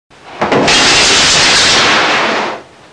Close Sound Effect
close-3.mp3